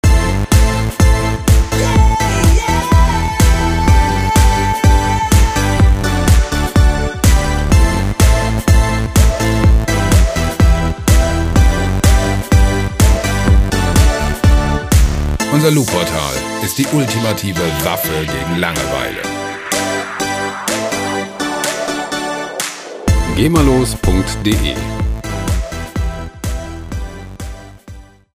Pop Loops GEMAfrei
Musikstil: Pop
Tempo: 125 bpm